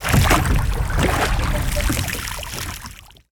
rowing.wav